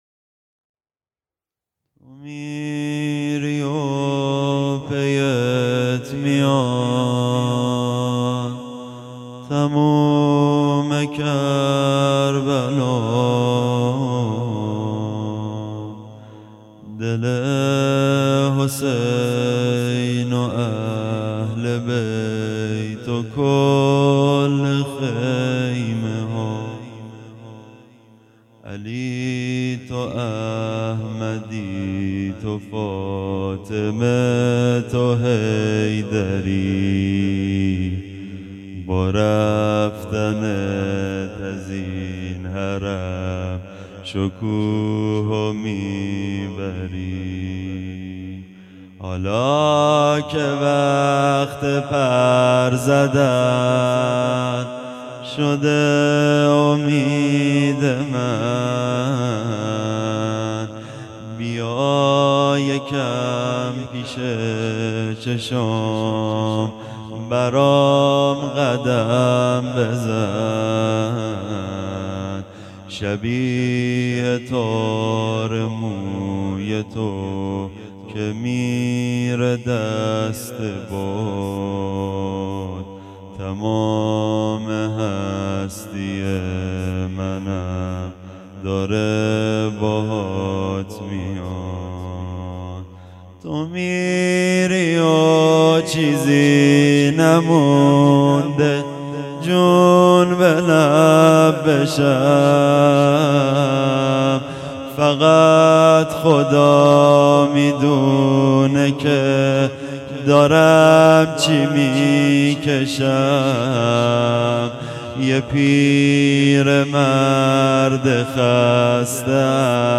شب هشتم محرم ۱۴۴۴